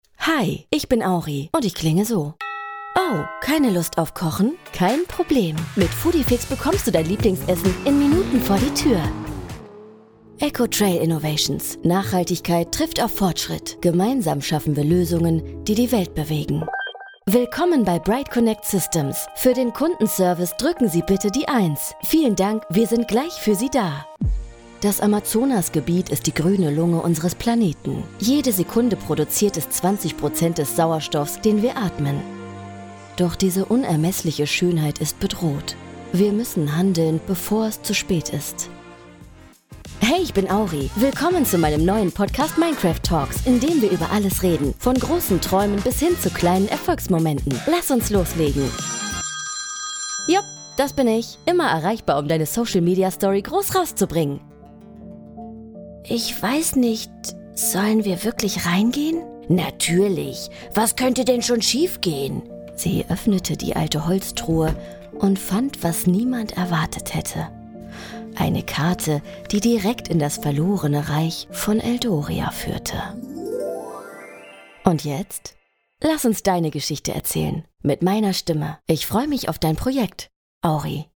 sehr variabel, hell, fein, zart, dunkel, sonor, souverän, plakativ, markant
Mittel minus (25-45)
Audio Drama (Hörspiel), Audiobook (Hörbuch), Audioguide, Commercial (Werbung), Doku, Comment (Kommentar), Imitation, Narrative, News (Nachrichten), Off, Overlay, Presentation, Scene, Station Voice, Tale (Erzählung), Tutorial, Wait Loop (Warteschleife)